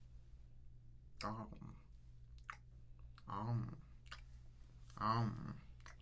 吃空气
描述：咀嚼空气的声音 可以用在饭后余味
标签： 咀嚼 吃东西
声道立体声